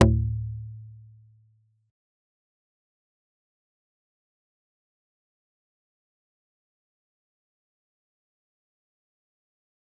G_Kalimba-D2-pp.wav